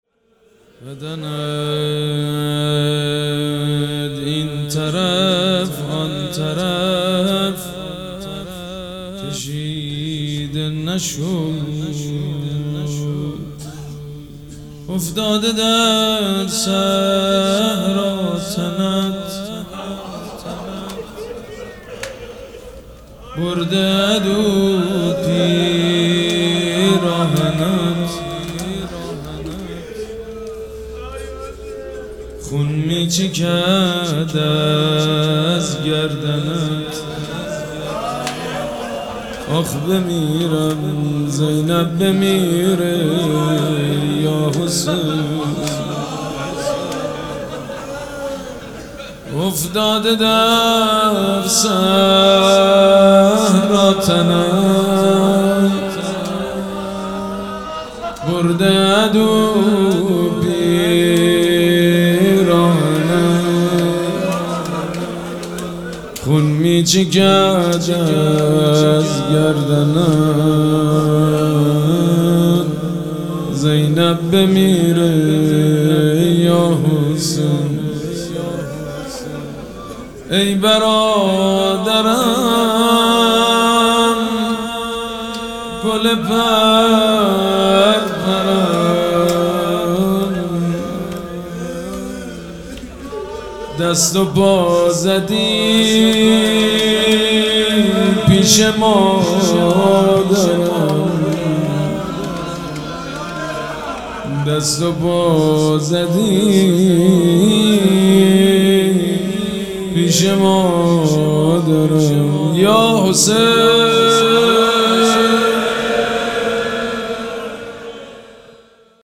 دانلود فایل صوتی روضه خوانی قتلگاه امام حسین (ع) مراسم شهادت امام جواد الائمه (ع) 1404 افتاده در صحرا تنت حاج سید مجید بنی فاطمه